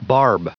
Prononciation du mot barb en anglais (fichier audio)
Prononciation du mot : barb